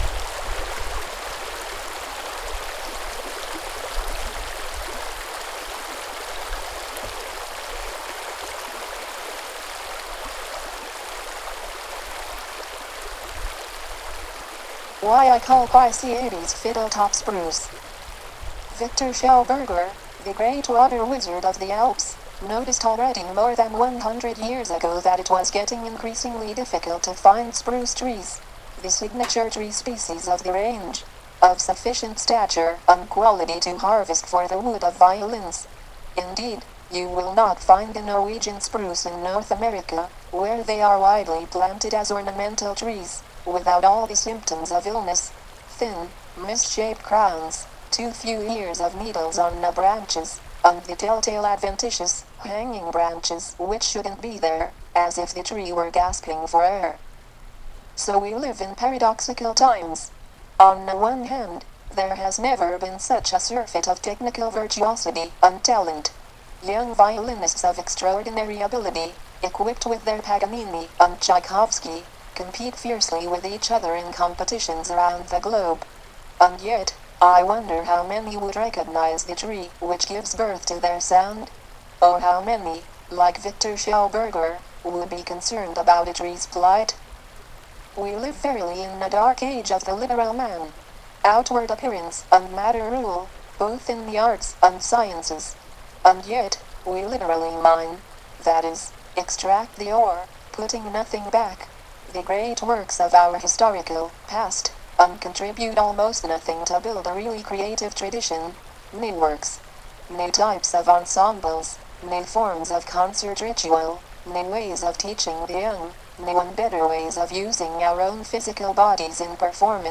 RECORDED w/ the P/P voice computer.